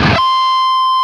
LEAD C 5 LP.wav